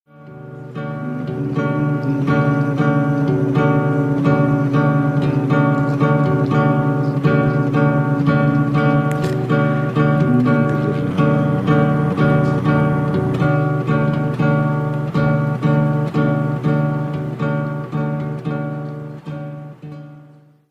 celebre battuta della chitarra